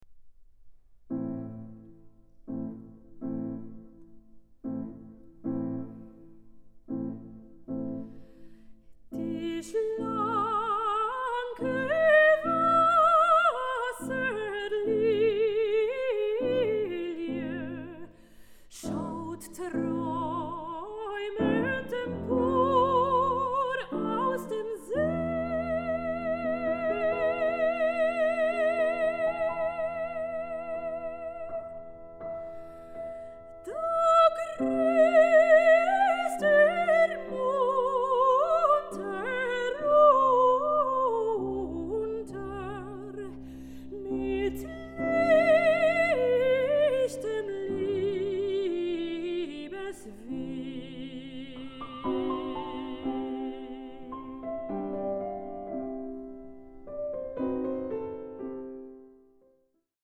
soprano
tenor
piano